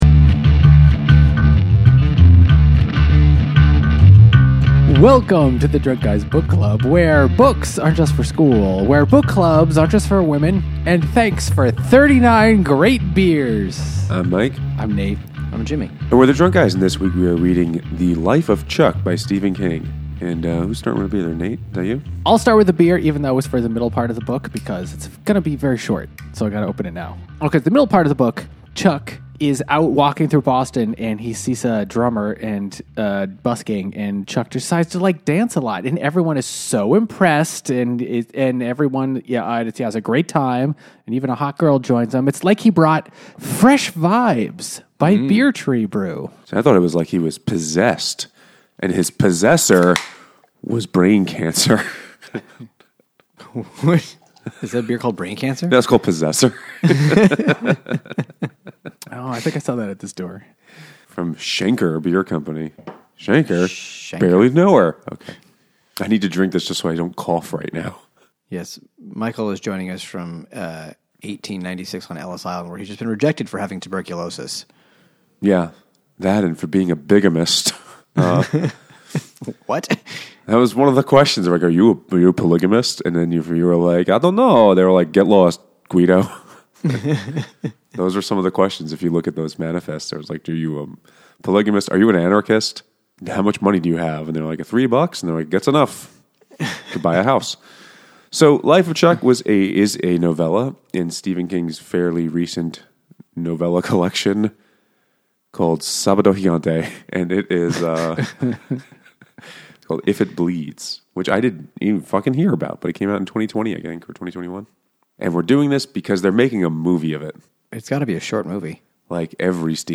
In this festive holiday episode, the dynamic duo gets to it at the uber decorated Mechanicsburg brewpub